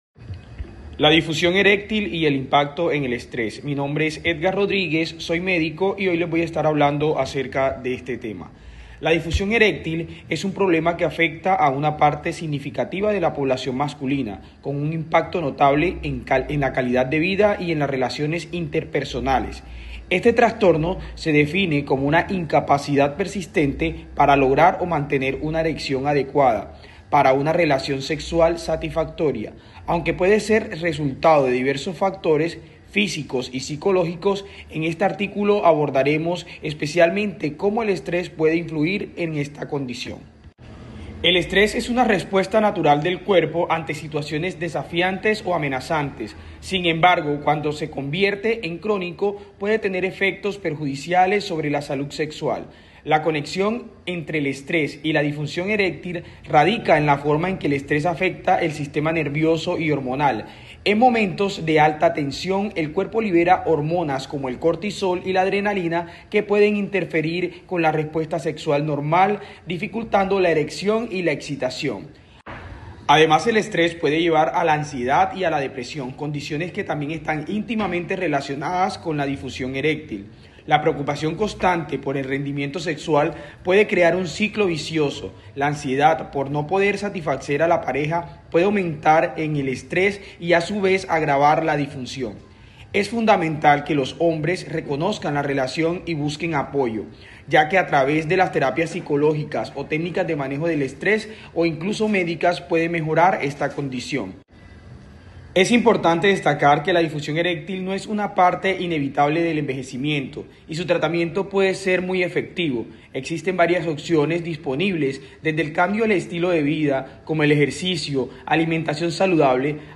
en diálogo con Caracol Radio